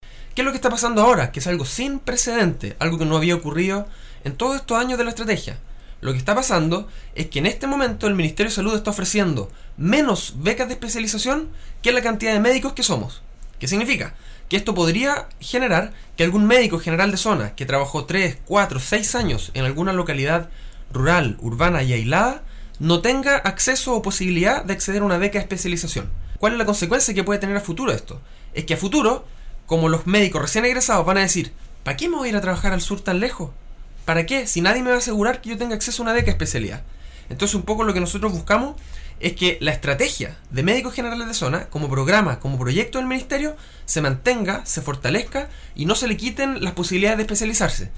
Señaló en entrevista con radio Estrella del Mar de Palena que la denominada estrategia de médicos generales de zona tiene un muy positivo impacto en atención de las poblaciones más aisladas y remotas del territorio nacional.